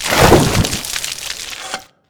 hand mining
wet1.wav